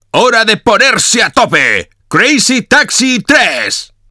Escucha las voces de algunos de los personajes antes de ser tratadas en post-producción con música y efectos sonoros.